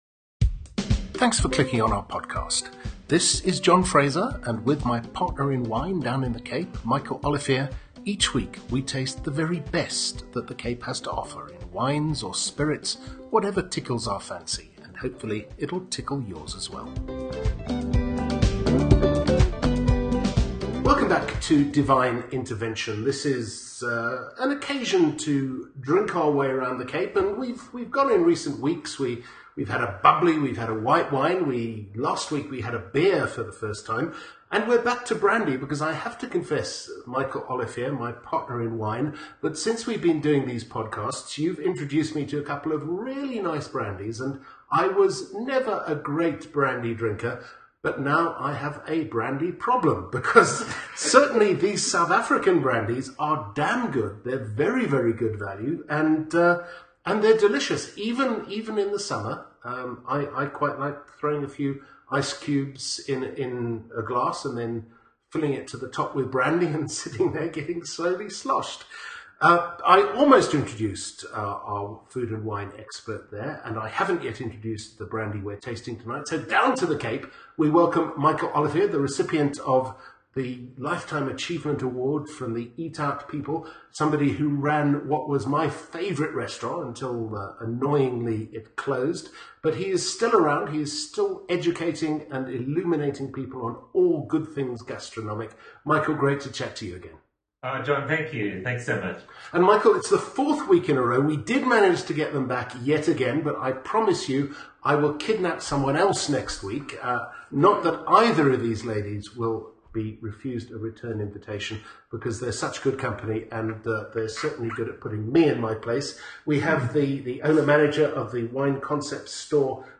There is also a chat about the best way to discover new wines and spirits, even for those on a budget….